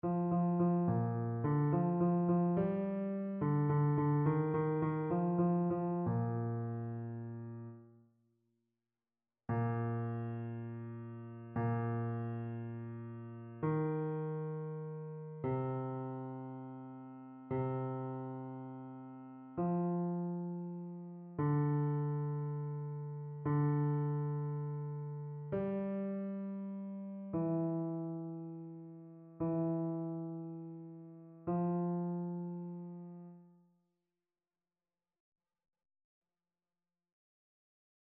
Basse